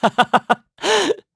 Siegfried-Vox_Happy3_kr.wav